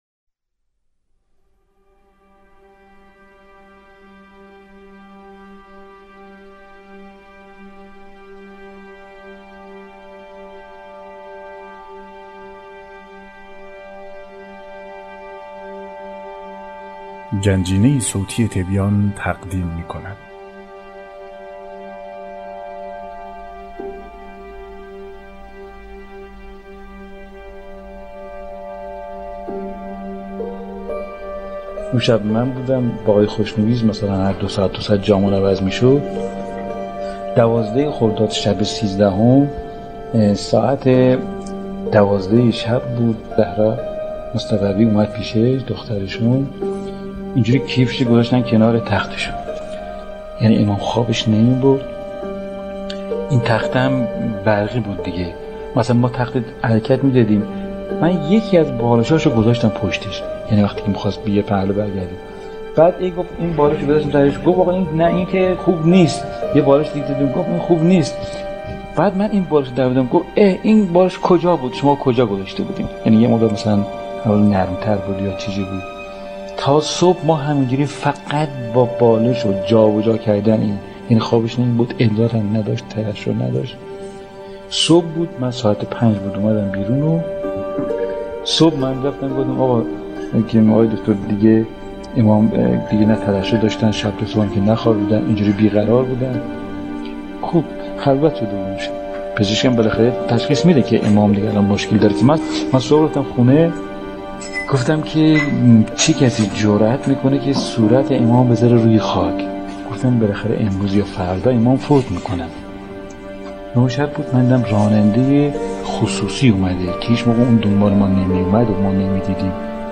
نواهنگی ناب